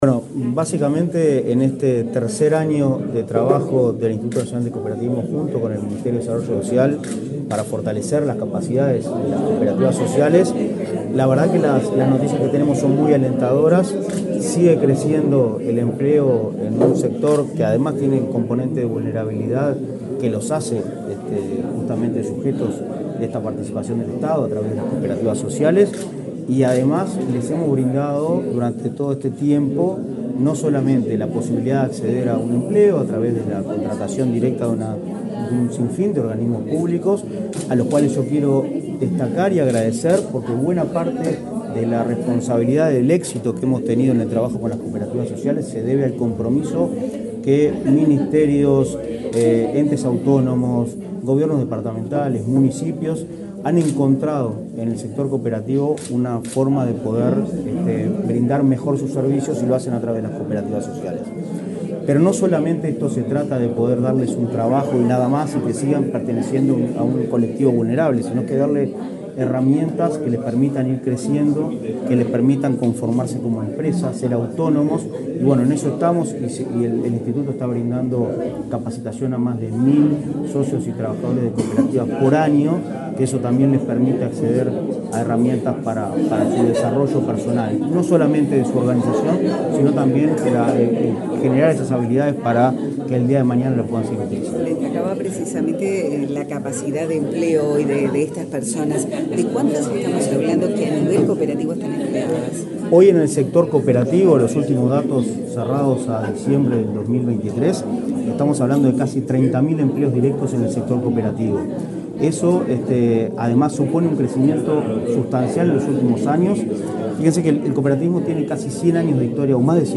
Declaraciones del presidente de Inacoop, Martín Fernández
Declaraciones del presidente de Inacoop, Martín Fernández 01/10/2024 Compartir Facebook X Copiar enlace WhatsApp LinkedIn Este martes 1.°, el presidente del Instituto Nacional del Cooperativismo (Inacoop), Martín Fernández, dialogó con la prensa, antes de participar en la presentación de datos acerca de las cooperativas sociales que integran el convenio entre el Ministerio de Desarrollo Social y el referido organismo.